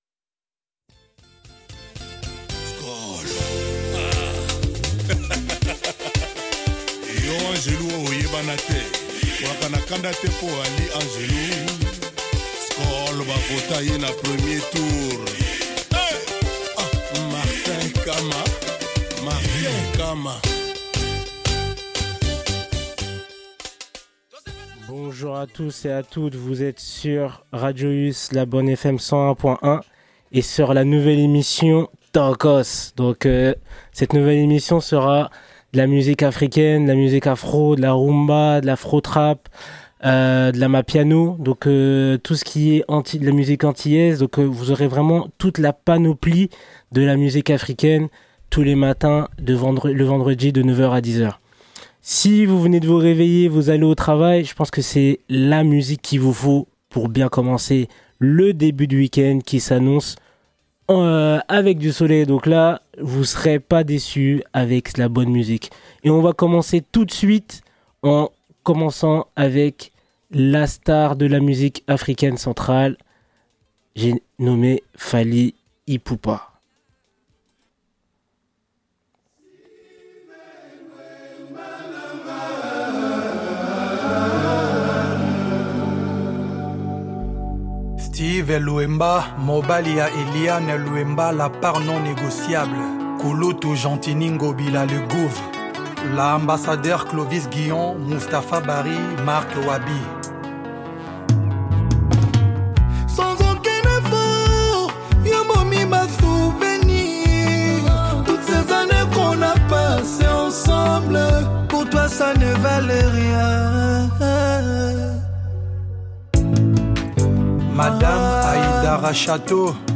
Tokoss c’est l’expression de la joie, de la bonne humeur où la musique africaine va entrer dans ton corps afin de te faire danser.